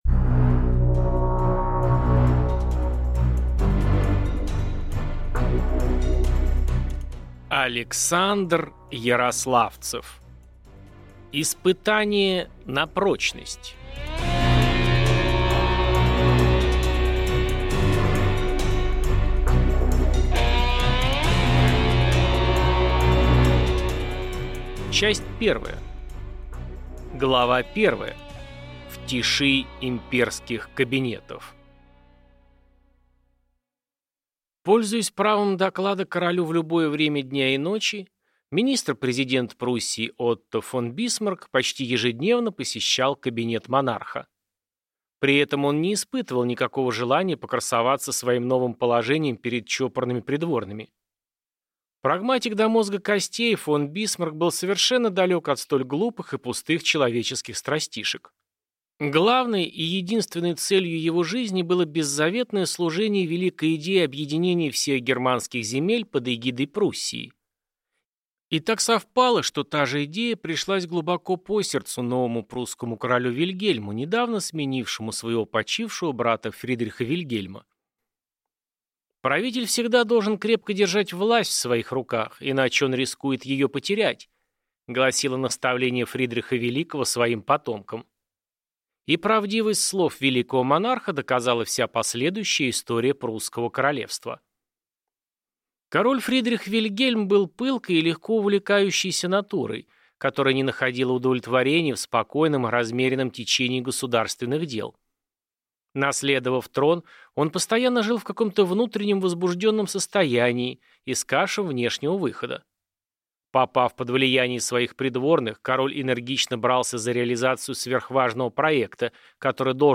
Аудиокнига Испытание на прочность | Библиотека аудиокниг